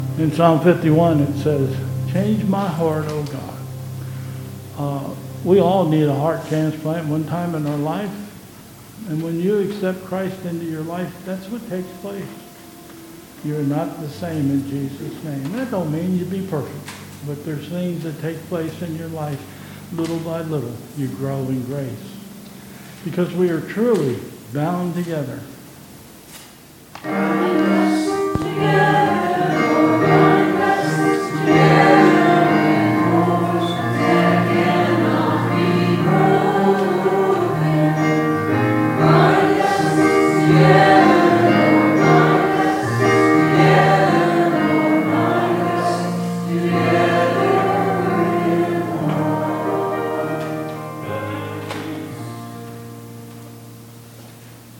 Closing remarks, Benediction and closing chorus